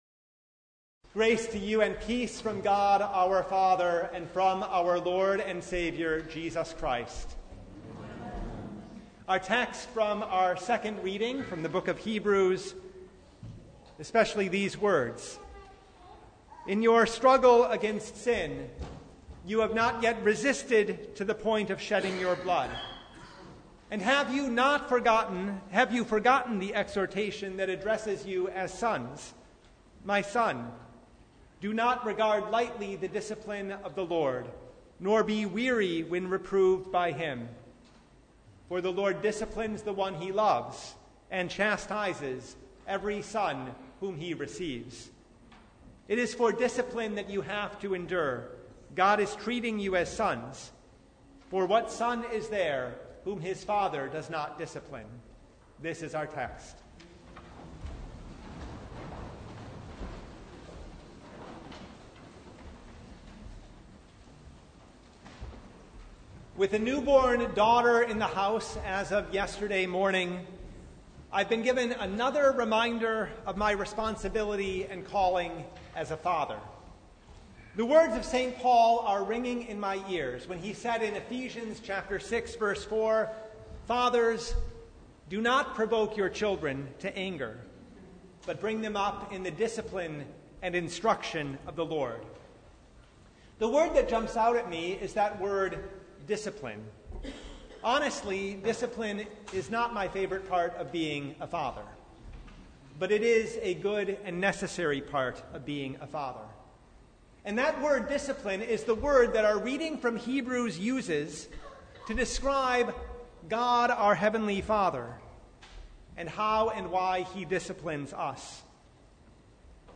Sermon from The Third Sunday in Martyrs’ Tide (2022)